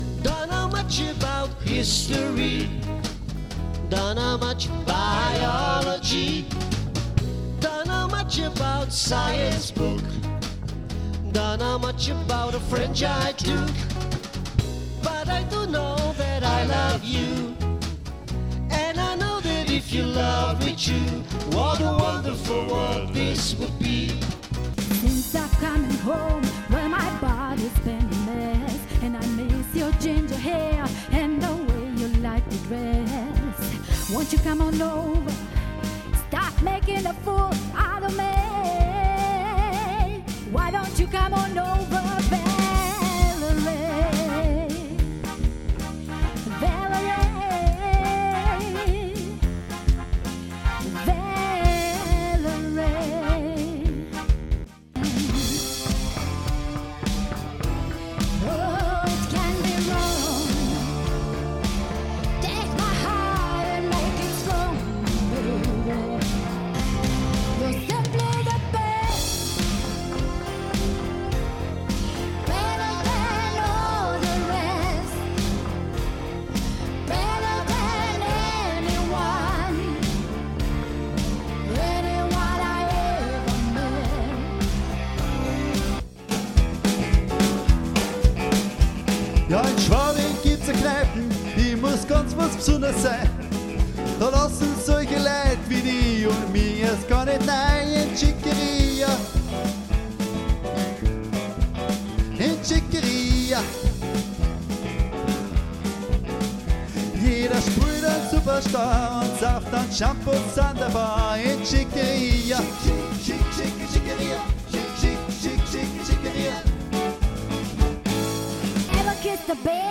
mit vier Leadsängern
• Allround Partyband
• Coverbands